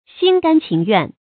xīn gān qíng yuàn
心甘情愿发音